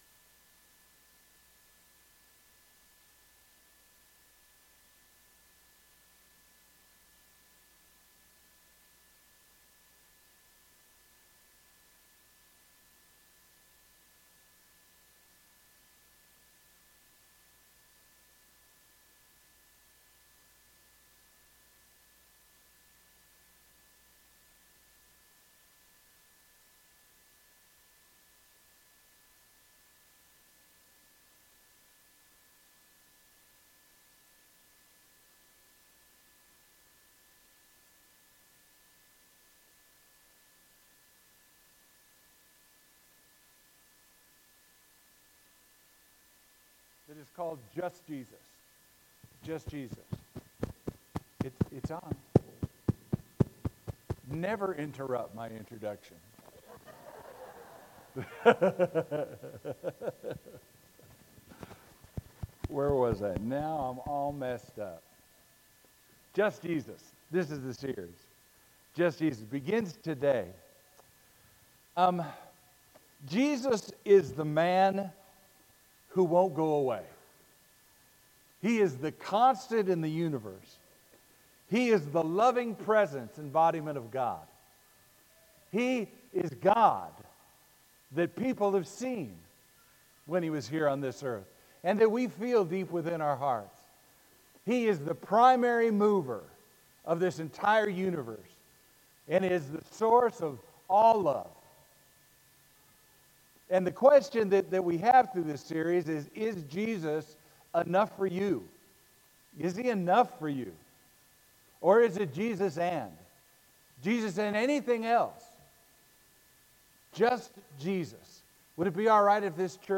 Sermon: “Just Jesus” (lesson 1)